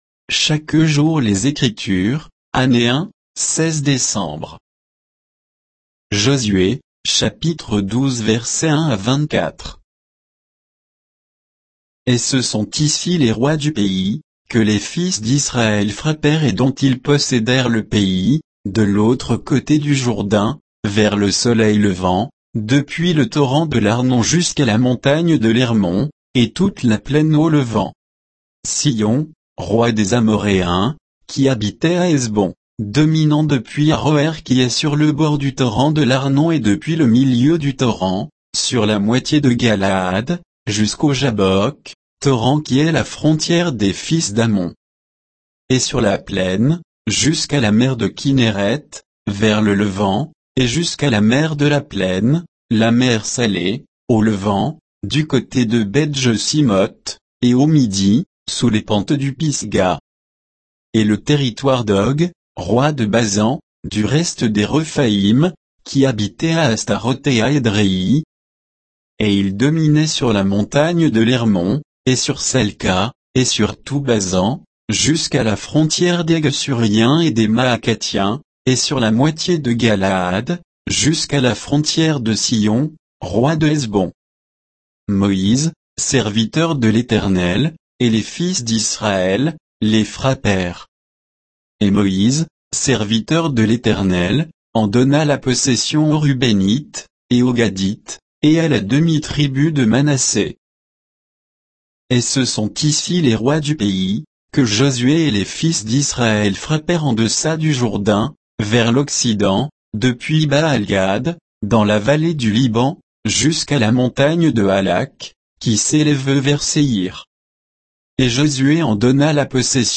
Méditation quoditienne de Chaque jour les Écritures sur Josué 12, 1 à 24